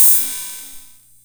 KR55_OH_01.wav